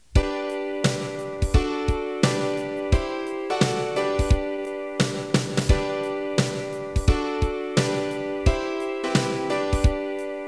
元データ(非圧縮WAV)の一部(約10秒)も比較のため掲載しました。